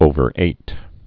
(ōvər-āt)